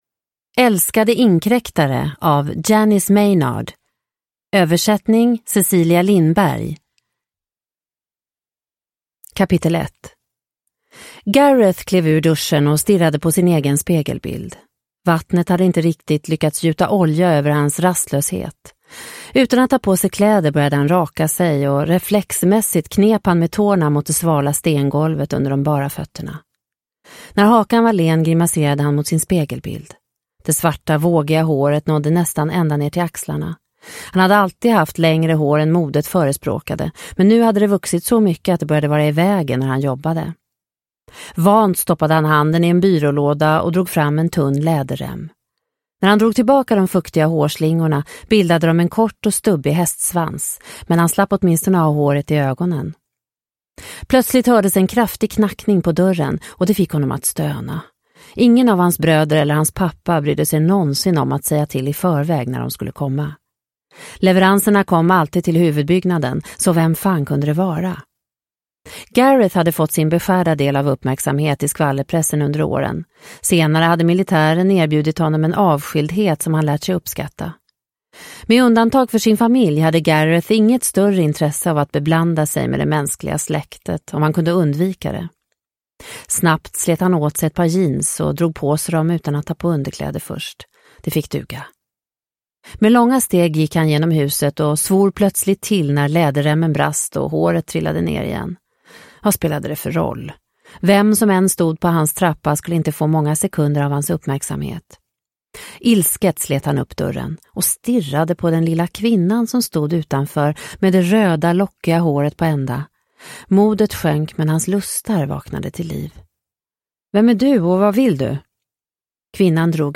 Älskade inkräktare – Ljudbok – Laddas ner